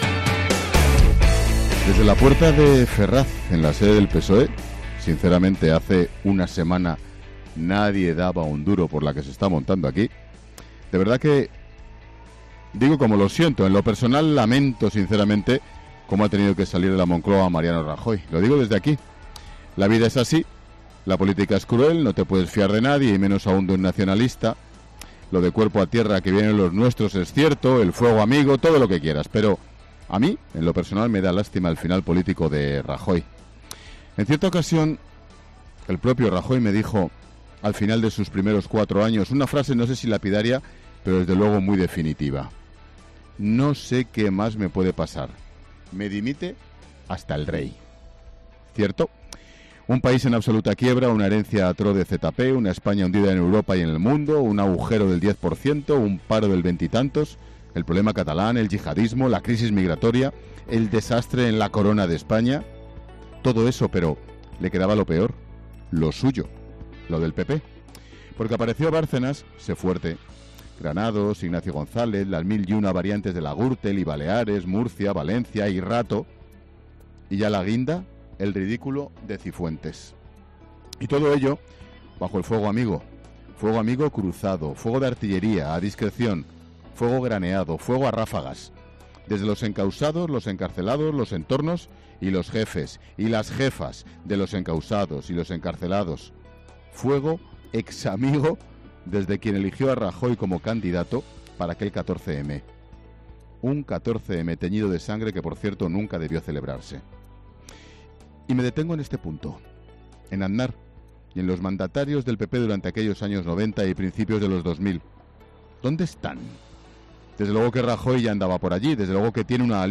Monólogo de Expósito
El comentario de Ángel Expósito desde la sede del PSOE tras ganar la moción de censura.